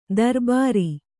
♪ darbāri